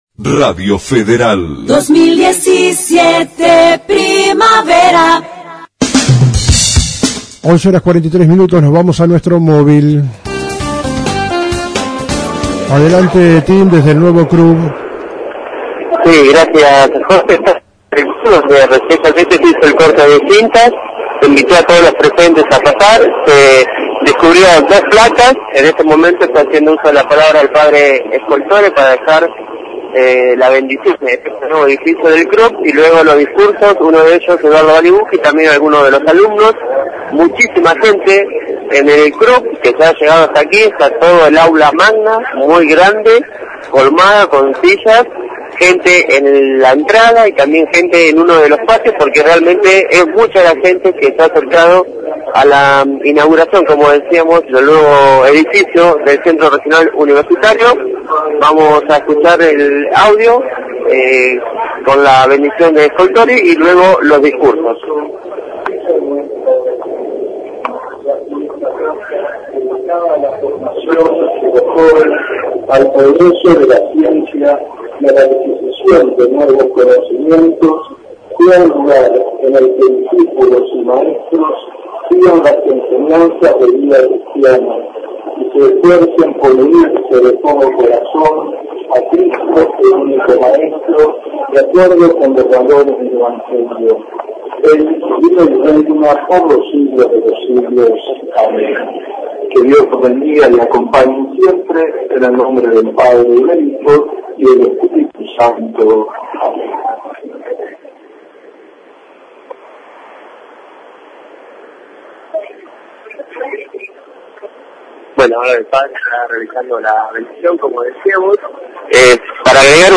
Se Inauguró el Nuevo CRUB :: Radio Federal Bolívar
Palabras alusivas